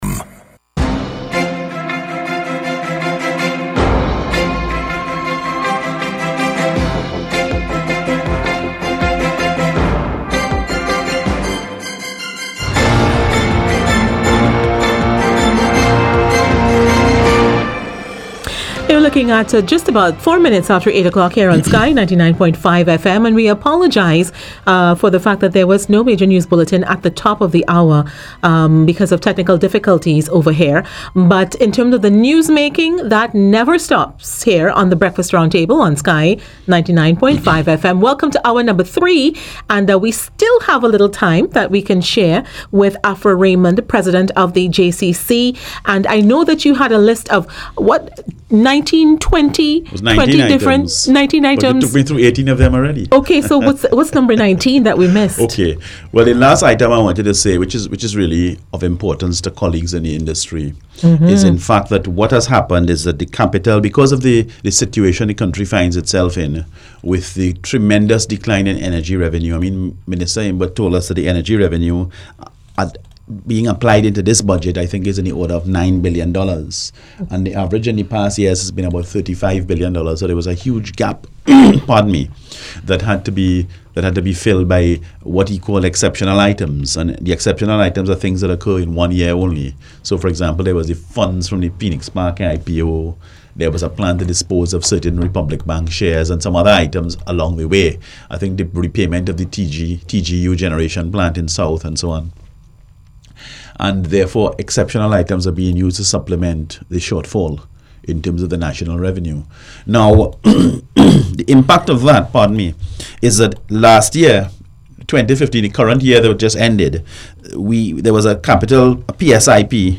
AUDIO: The Breakfast Roundtable interview on Sky 99.5FM- 7 October 2015